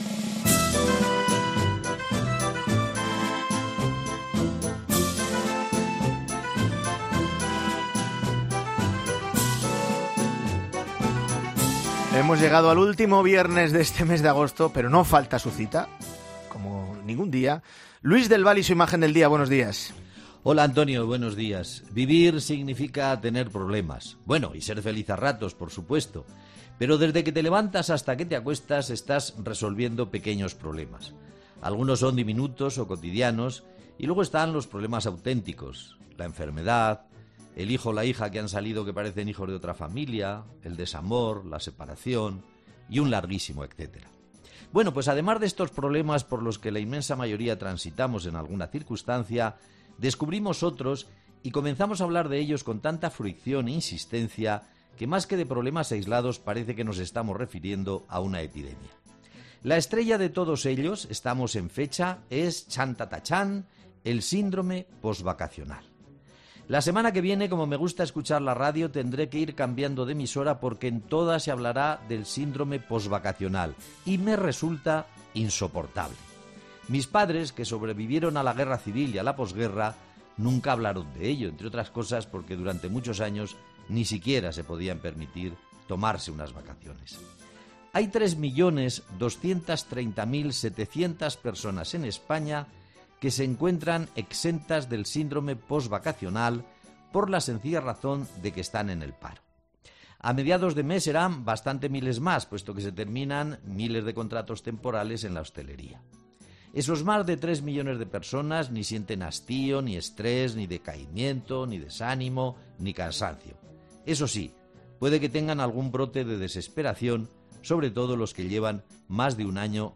El periodista se despide de las vacaciones de verano en 'Herrera en COPE'